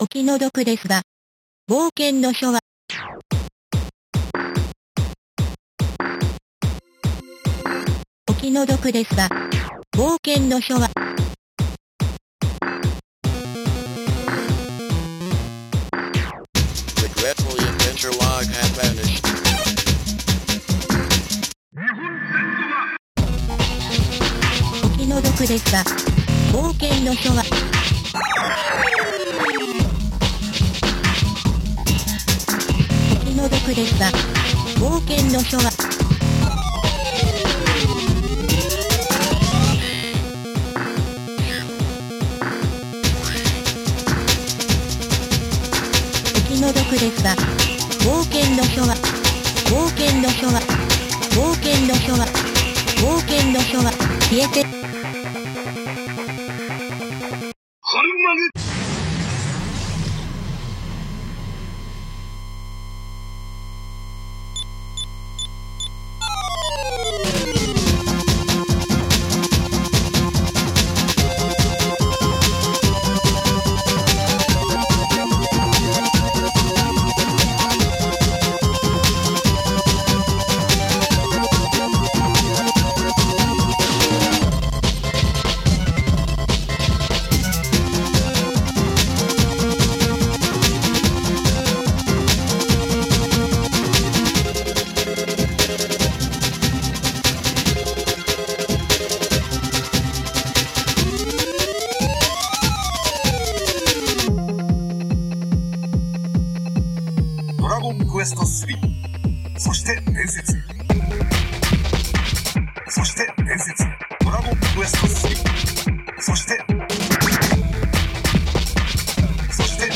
Magical 8bit Plug
macOS Text-to-Speech